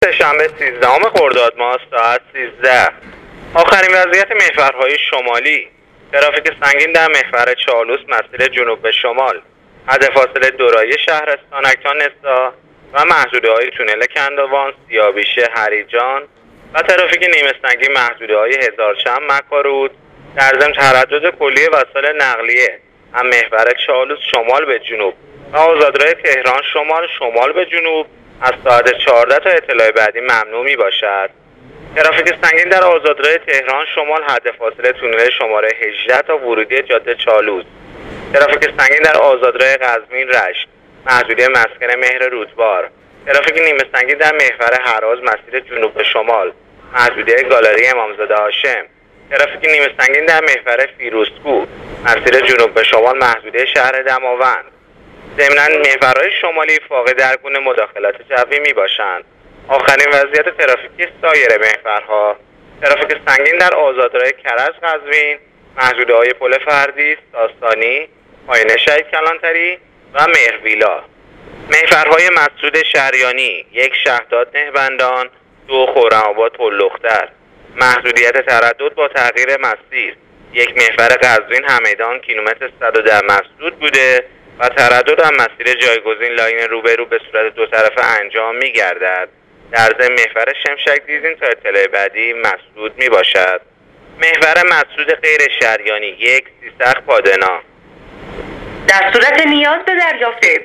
گزارش رادیو اینترنتی از وضعیت ترافیکی جاده‌ها تا ساعت ۱۳ سیزدهم خردادماه